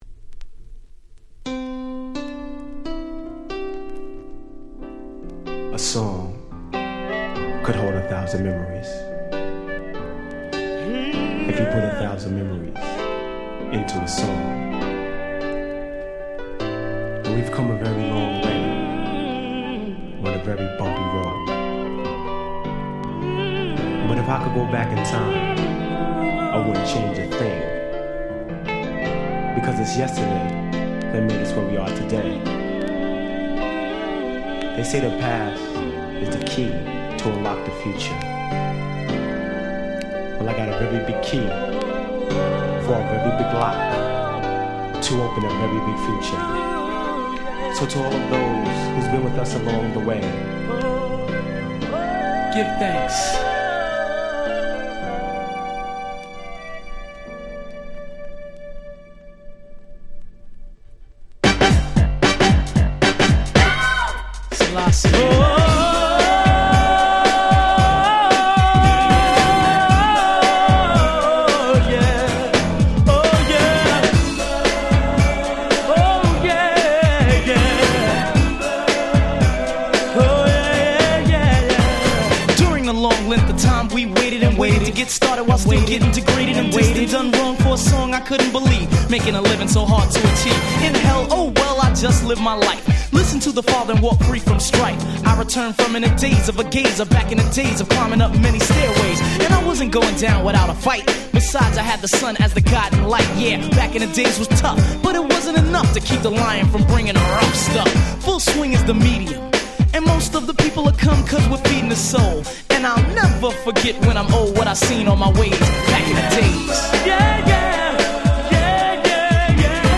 91' Very Nice Hip Hop / New Jack Swing / R&B Album !!
エフエスエフェクト NJS 90's ニュージャックスウィング